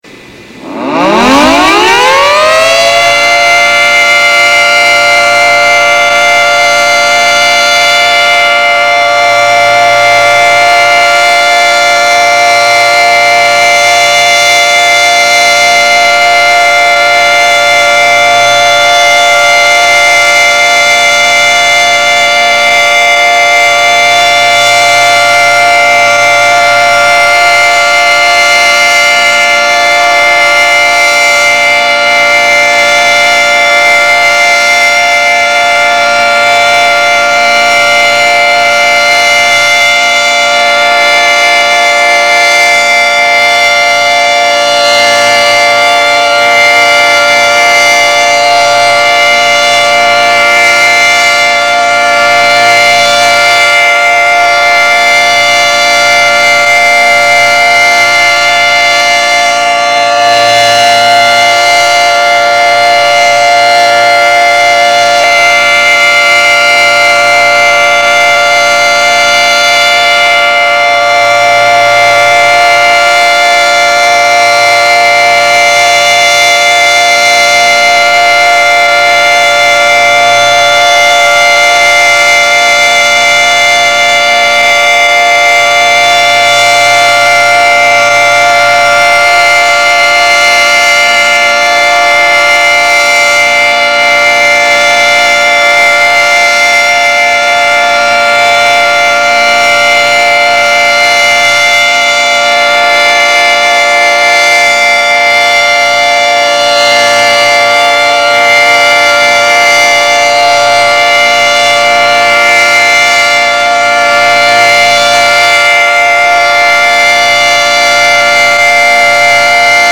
These files are a collection of siren sounds that I have downloaded and made available for all to listen to.
The first 4 files are computer-edited.
Federal 2t22 Alert
2t22Alert.mp3